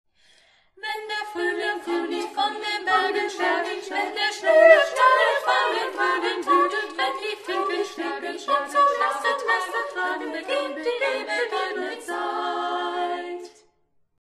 Das Lied wurde für Sie vom Sextett der Freien Waldorfschule Halle eingesungen.
Kanon Update needed